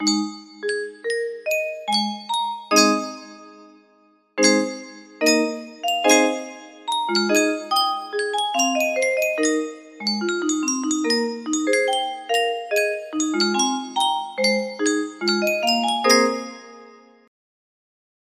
Yunsheng Music Box - Butterfly Lovers Y514 music box melody
Full range 60